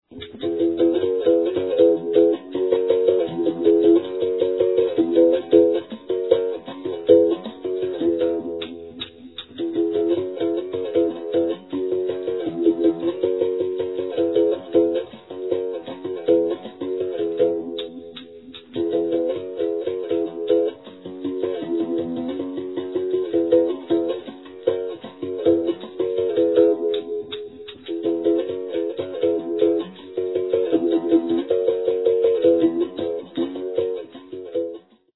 traditional music of the Capoeira
plays the berimbau